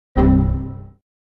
WindowsXPError.ogg